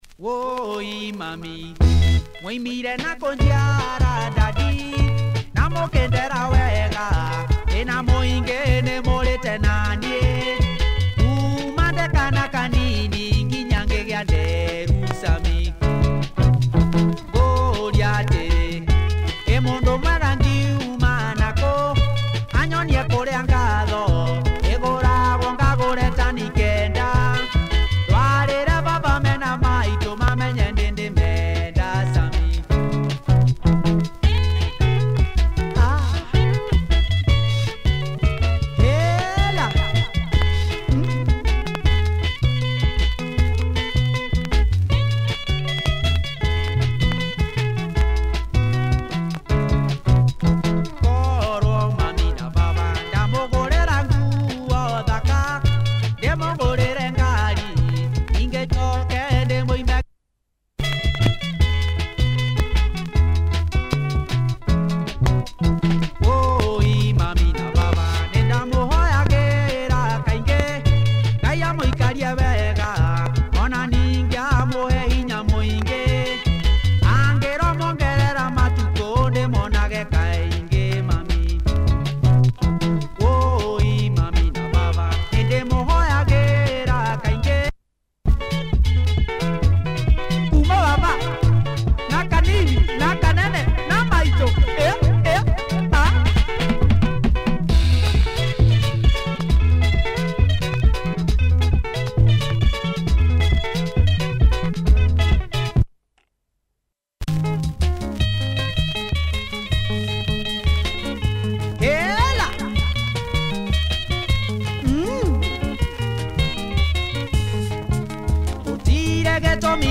kikuyu music